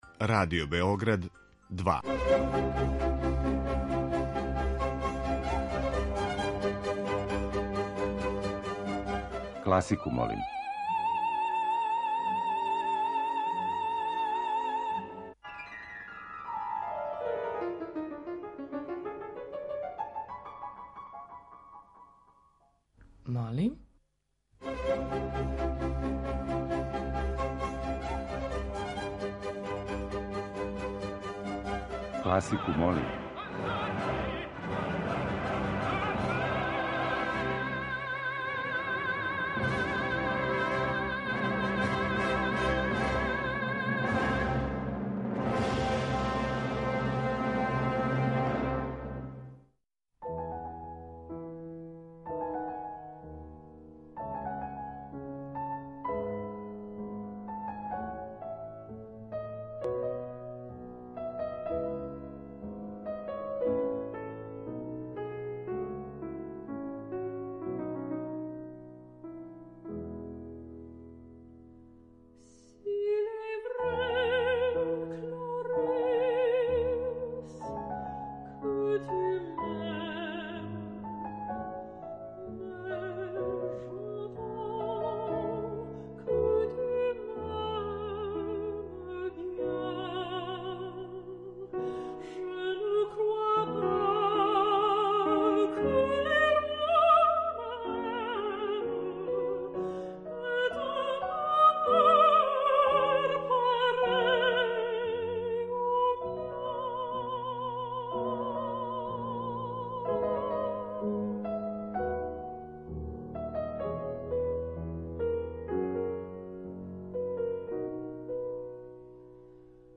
И ове седмице слушаоцима ће бити понуђени разноврсни предлози из домена класичне музике.
Стилски и жанровски разноврсни циклус намењен и широком кругу слушалаца који од понедељка до четвртка гласају за топ листу недеље.